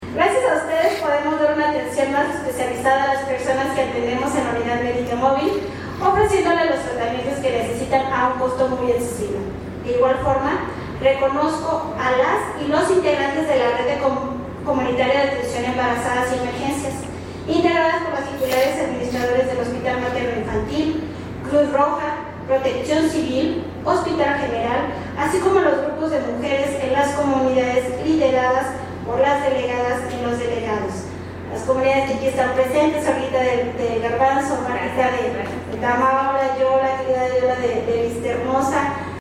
AudioBoletines
Liliana Flores Rodríguez, regidora